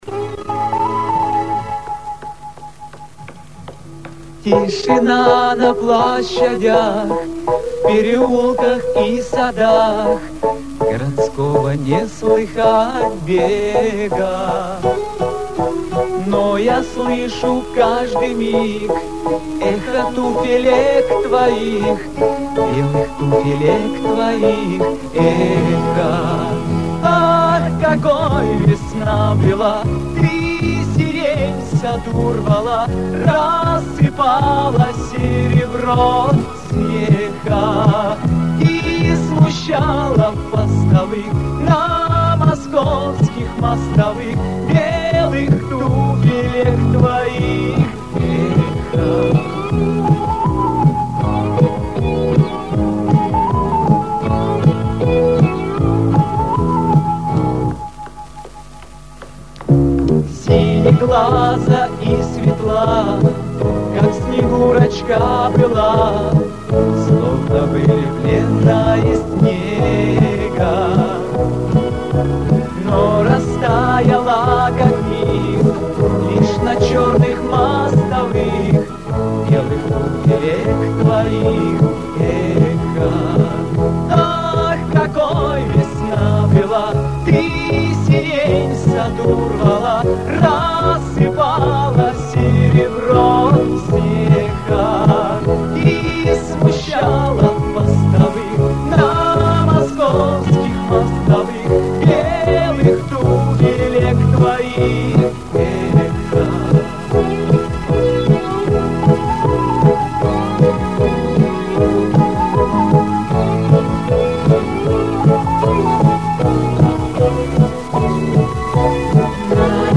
Определите, пожалуйста, какой ВИА поёт?